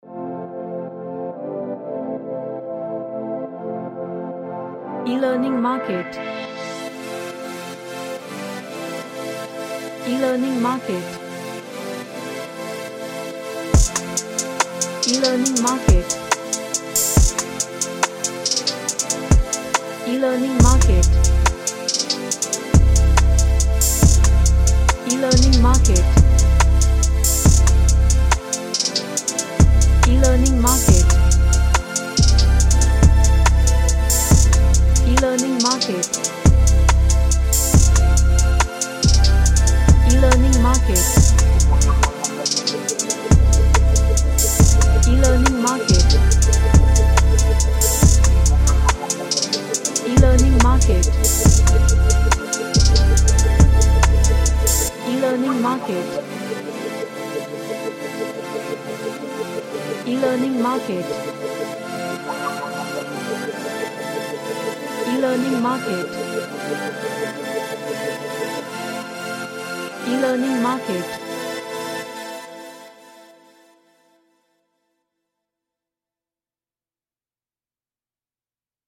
A synth hyped electronic track
Sci-Fi / Future